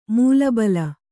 ♪ mūla bala